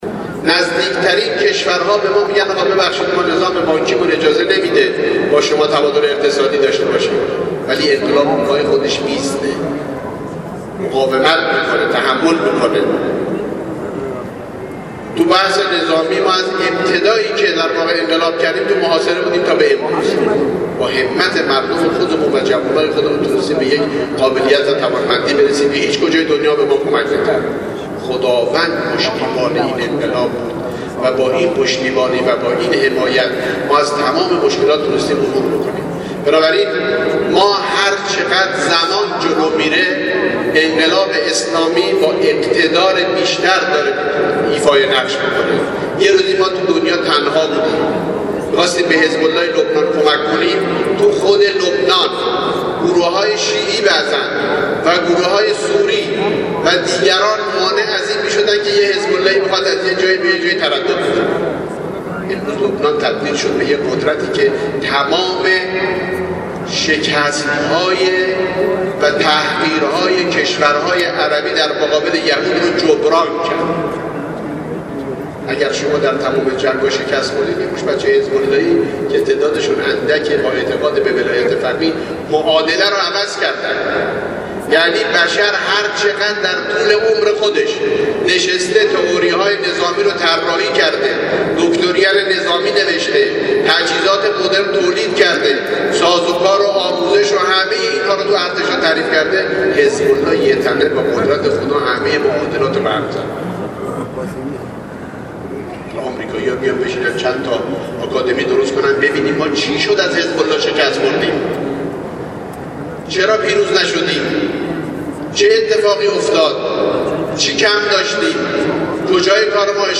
به گزارش خبرنگار سیاسی خبرگزاری رسا، سردار حسین اکبری معاون فرهنگی سپاه قدس عصر امروز در همایش تبیین بیانیه گام دوم انقلاب و الزامات آن که در مصلای قدس قم برگزار شد، با اشاره به توطئه های دشمن برای نابودی انقلاب اسلامی گفت: باید باور کنیم که آمریکا از غلبه بر جمهوری اسلامی عاجز است، زیرا اگر ذره ای احتمال موفقیت داشت لحظه ای درنگ نمی کرد.